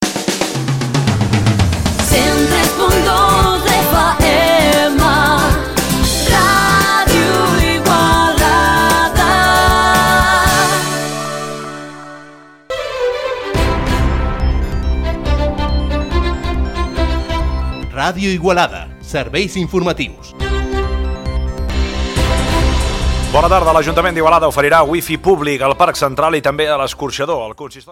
Indicatius de l'emissora i primer titular de l'informatiu: l'ajuntament d'Igualada amplia el seu servei de Wifi públic.
Informatiu
Enregistrament extret del programa "Les Veus dels Pobles" de Ràdio Arrels.